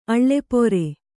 ♪ aḷḷepore